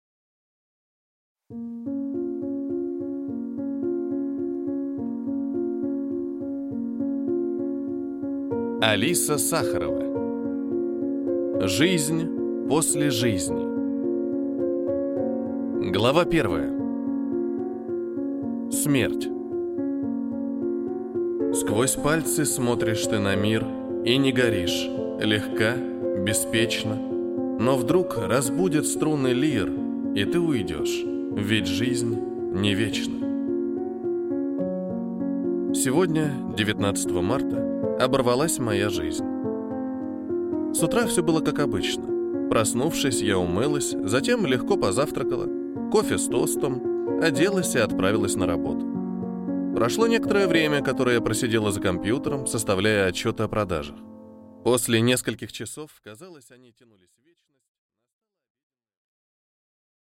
Аудиокнига Жизнь после жизни: между мирами | Библиотека аудиокниг